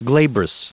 Prononciation du mot glabrous en anglais (fichier audio)
Prononciation du mot : glabrous